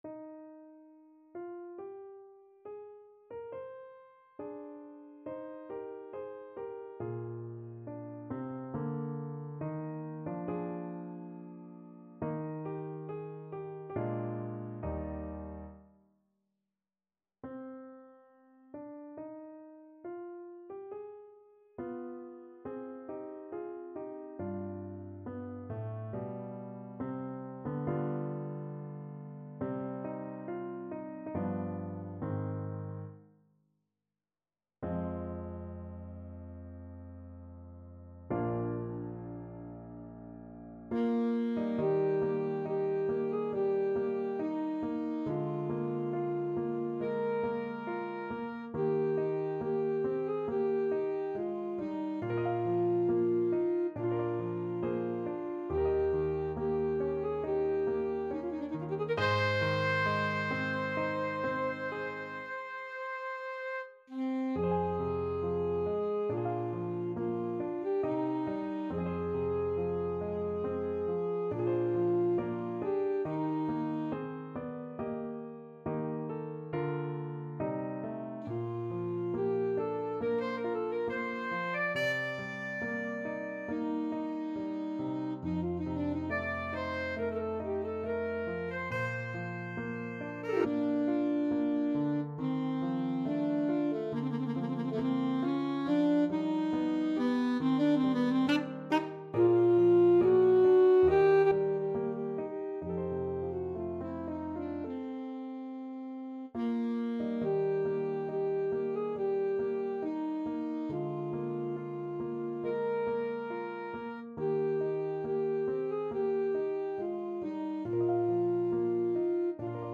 Classical Chopin, Frédéric Piano Concerto No.1 (Op. 11) Second Movement Main Theme Alto Saxophone version
4/4 (View more 4/4 Music)
Eb major (Sounding Pitch) C major (Alto Saxophone in Eb) (View more Eb major Music for Saxophone )
Larghetto (=80) =69
Classical (View more Classical Saxophone Music)
chopin_piano_con1_2nd_mvt_ASAX.mp3